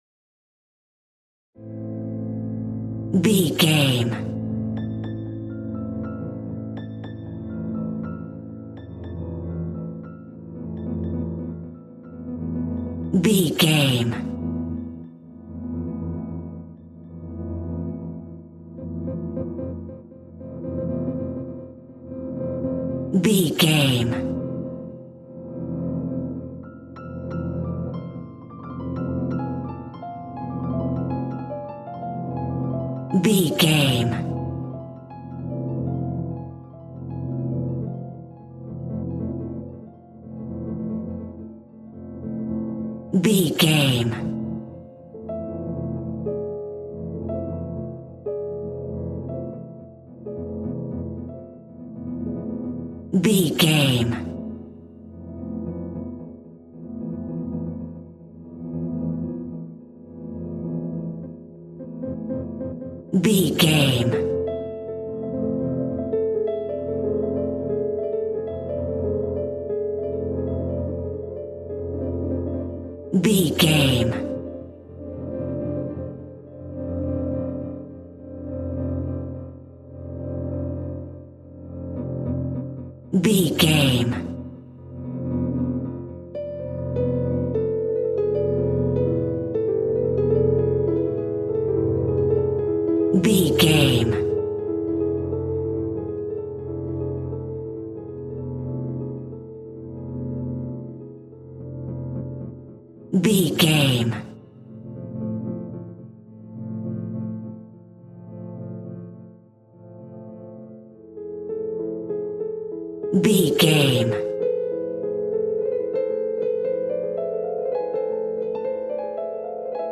Atonal
Slow
tension
ominous
dark
haunting
eerie
synthesizer
piano
ambience
pads
eletronic